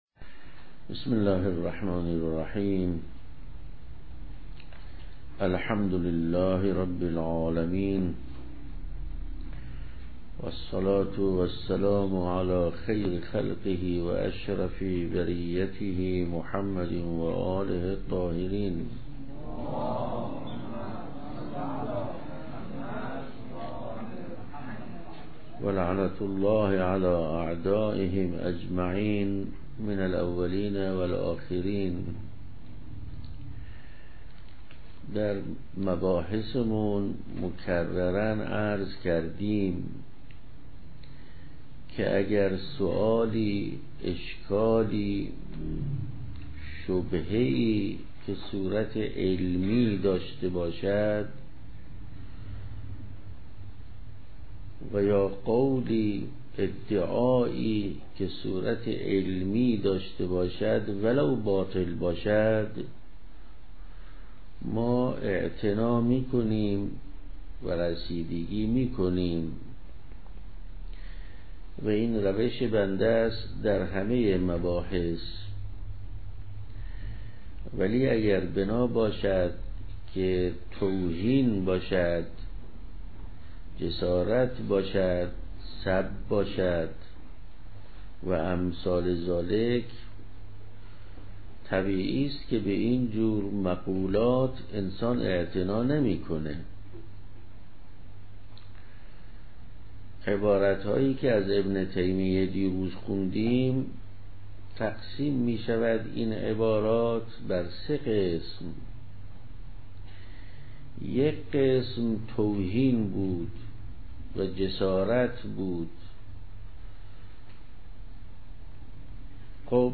سلسله دروس امامت وولایت - مهدویت - ۴۱۸